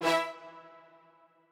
strings13_13.ogg